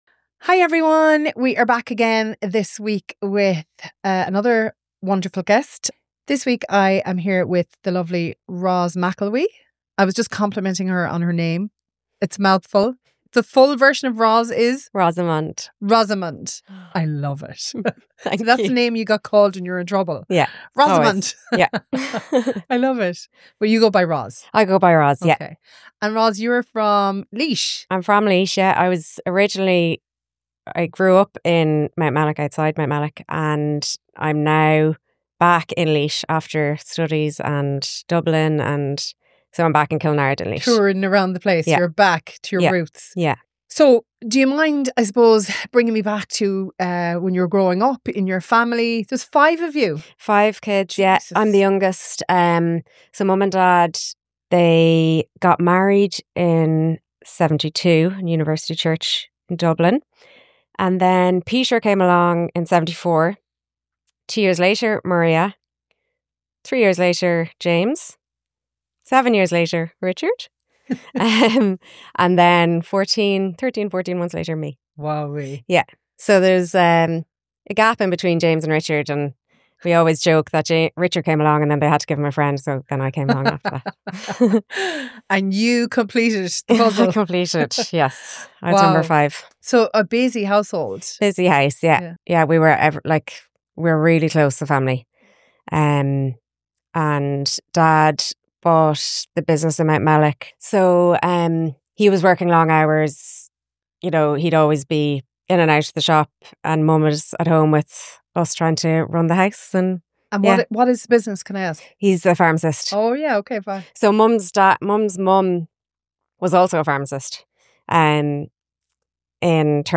This is a conversation about layered loss, grieving a parent while also navigating the heartbreak and hope that comes with fertility challenges and the strength it takes to keep going when life doesn’t unfold as expected.